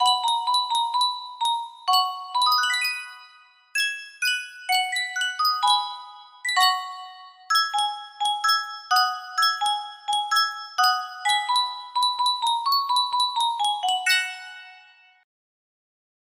Sankyo Music Box - Tchaikovsky Dance of the Reed Flutes BZC music box melody
Full range 60